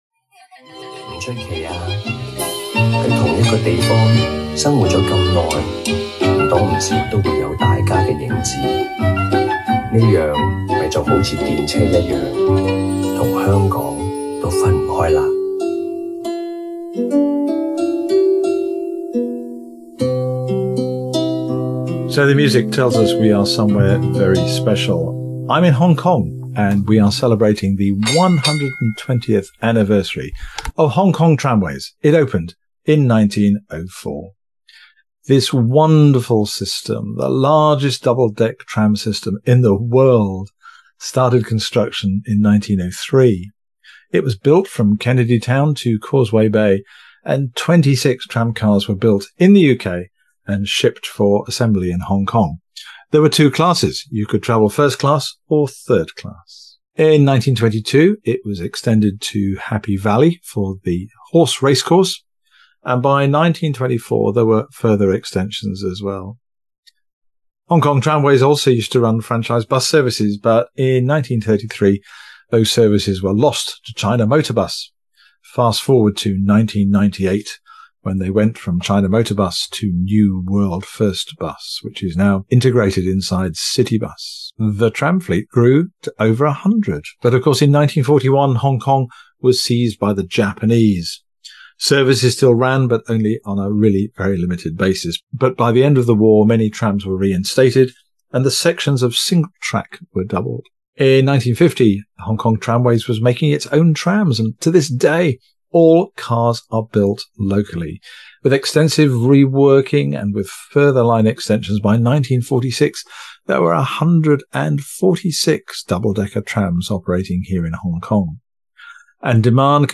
A special episode of the show, with live speakers from the recent 120th anniversary of the Hong Kong Tramway, affectionately known by locals as the 'Ding Ding'.